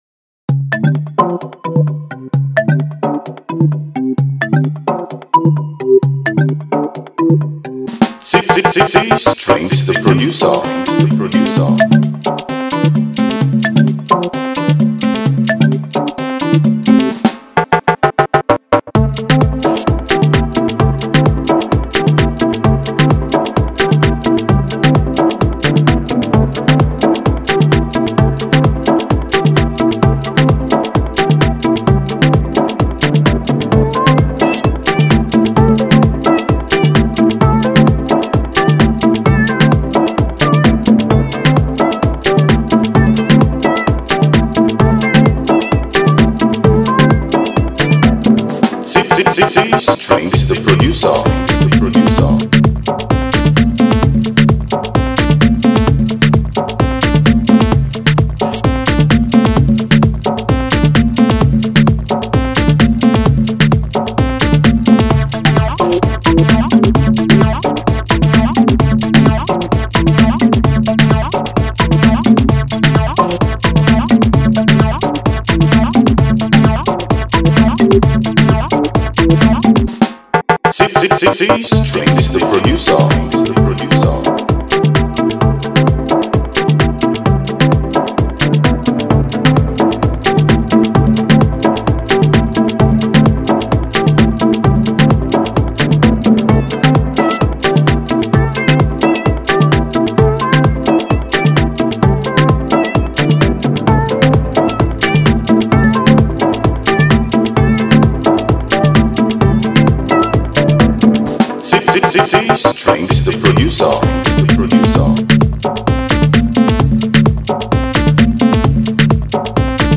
an Instrumental track